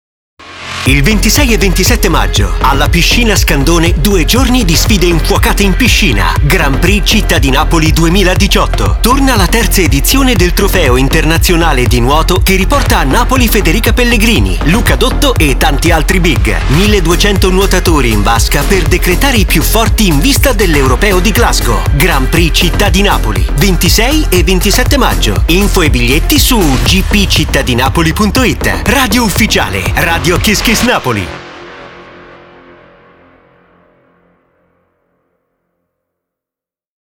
LO SPOT RADIO 2018